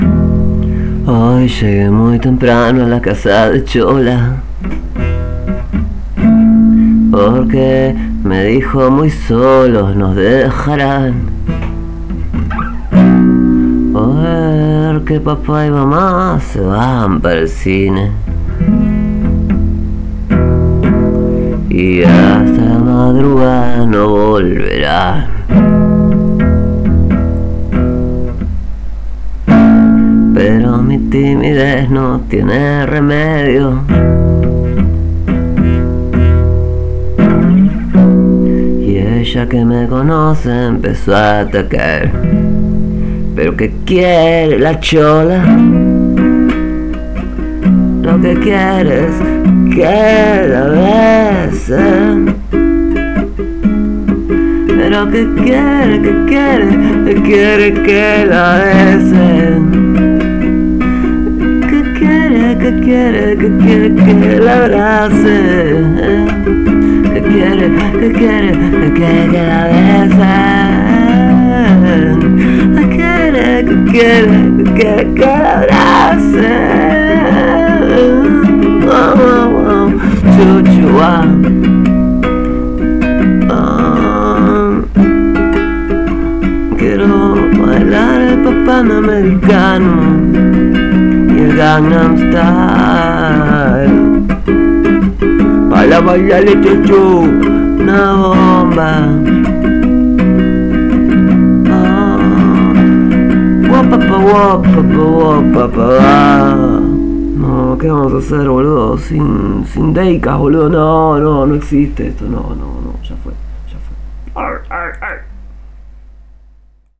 Un cover